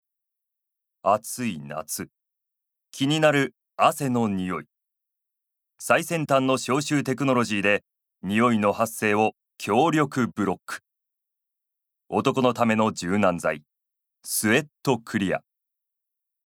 ボイスサンプル
ナレーション３